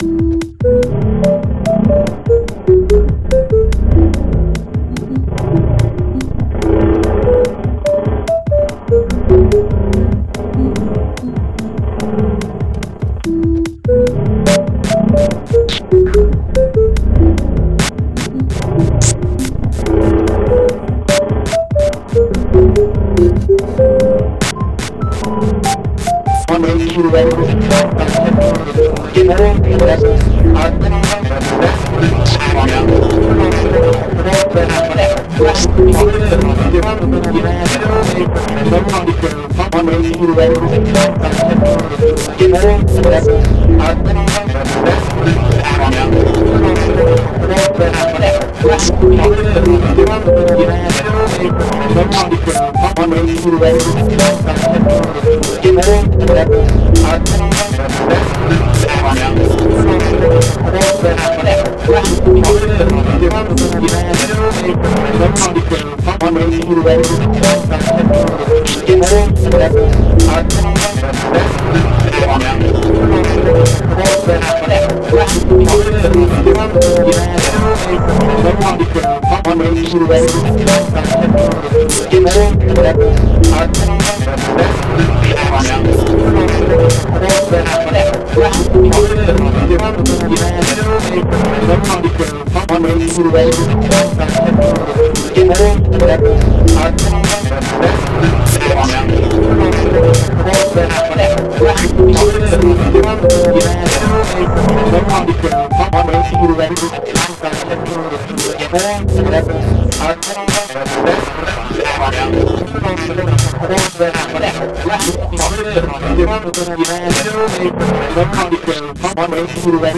keyboard flute